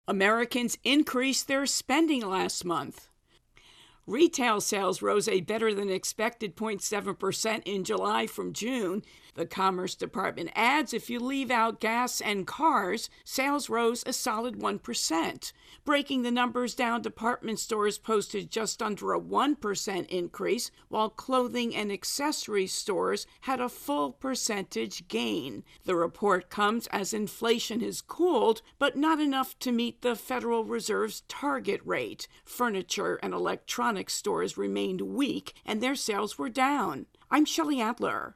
AP correspondent